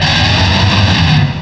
cry_not_druddigon.aif